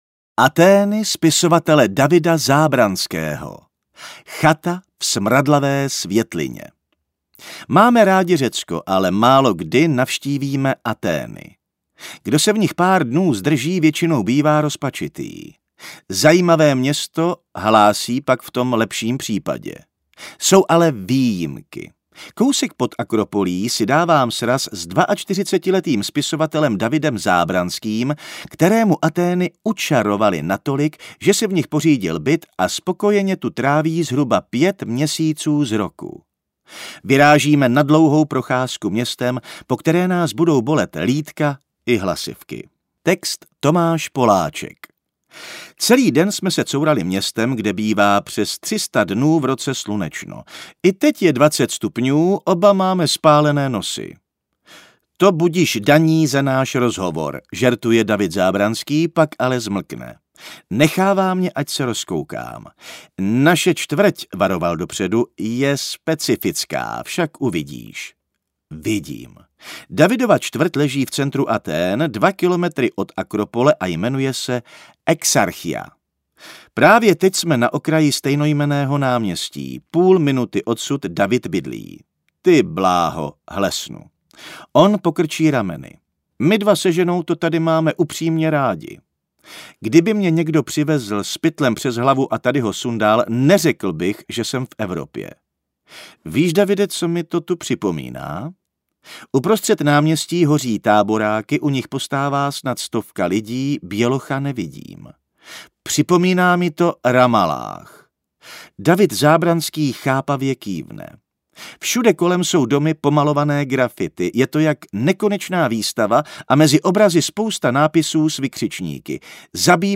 Reportér listopad 2022 – Audiotéka ve spolupráci s magazínem Reportér představuje měsíčník Reportér v audio verzi.